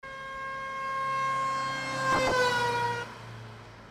Klaxon voiture en mouvement
Klaxon - Voiture mouvement.mp3